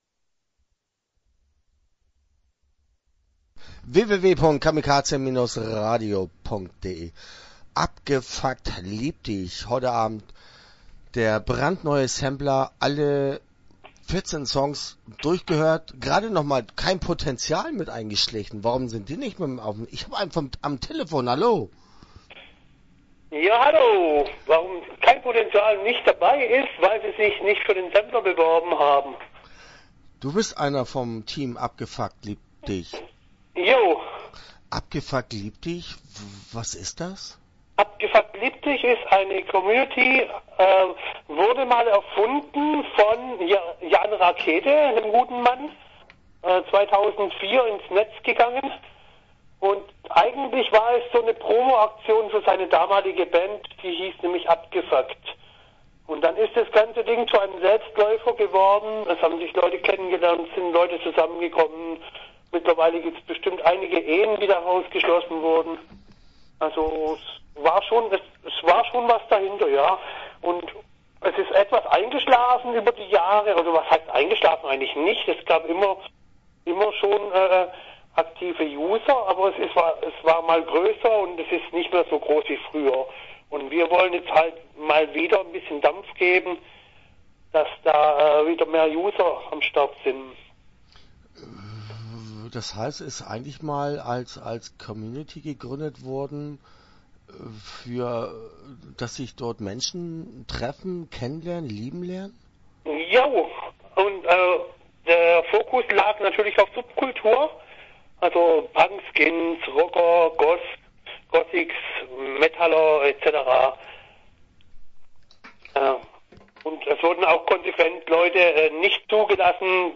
Interview (16:59)